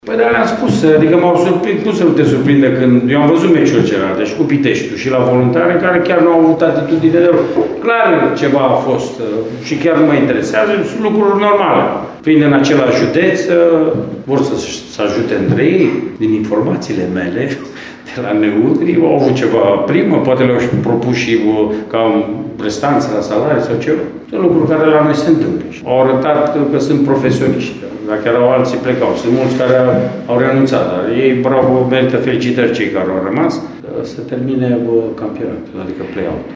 Neînvins în campionat de la preluarea băncii tehnice, Rednic a vorbit şi despre susţinerea pe care Mioveniul a primit-o de la FC Argeş, vorbind chiar şi de o eventuală primă promisă retrogradatei, de către conduderea alb-violetă: